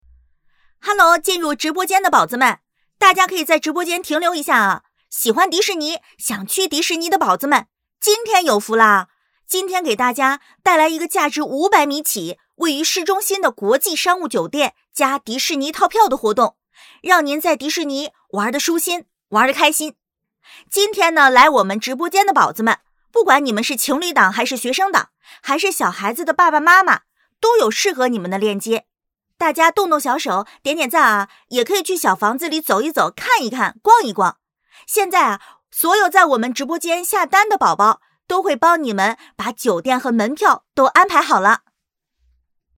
女26号配音师